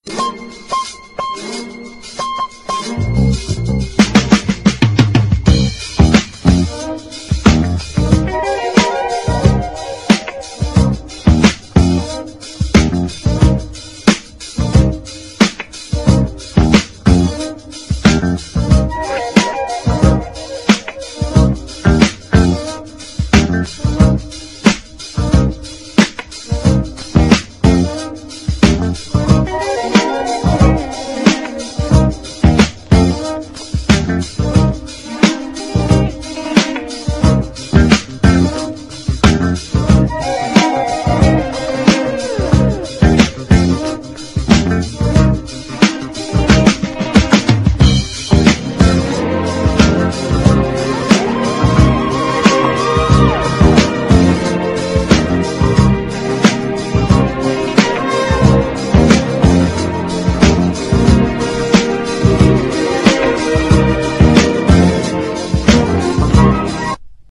JAZZ / DANCEFLOOR / JAZZ FUNK / SAMPLING SOURCE / DRUM BREAK
サンプリング～DJネタになってるビッグバンド・ジャズ・ファンク人気作！
ロックやポップの名曲を大胆にビッグバンド・アレンジで再構築した、グルーヴィーなジャズ・ロック～ジャズ・ファンク傑作。